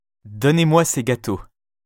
les sons [ o ] bureau, tôt [ ɔ ] bonne, prof